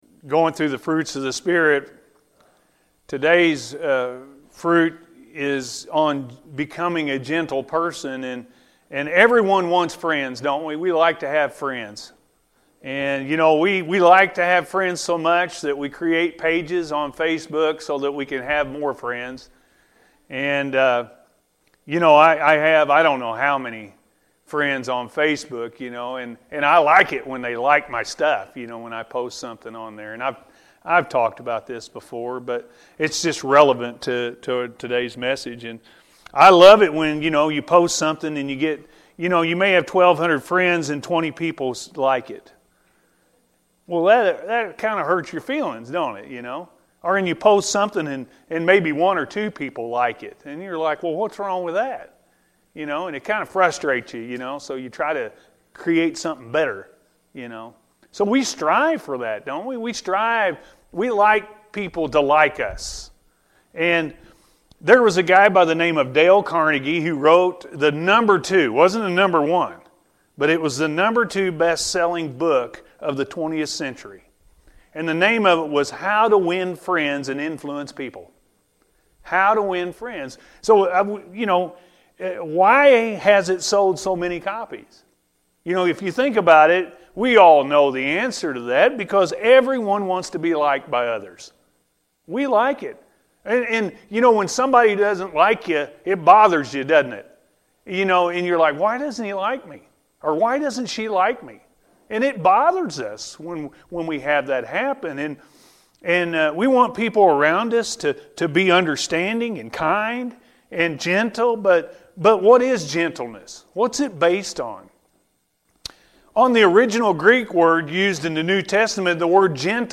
Gentleness-A.M. Service